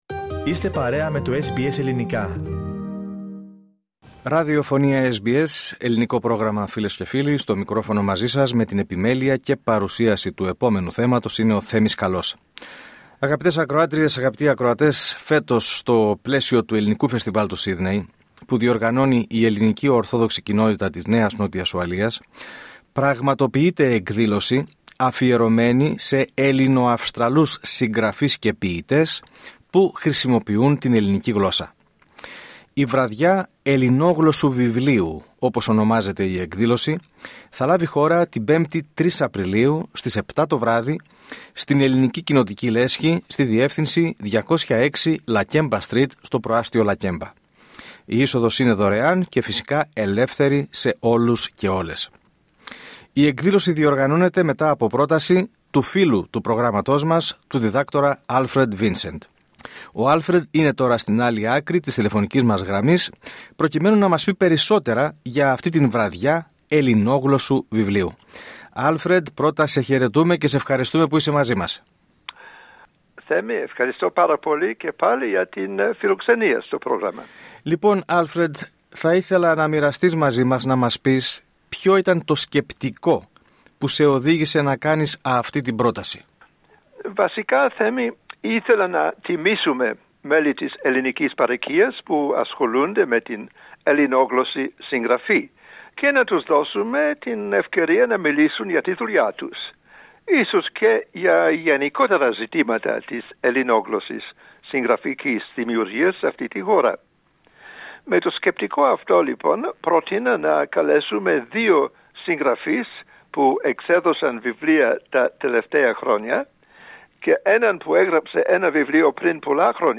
ο οποίος ανέπτυξε το σκεπτικό της εισήγησής του σε συνέντευξή του προς το Πρόγραμμά μας